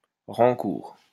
Rancourt (French pronunciation: [ʁɑ̃kuʁ]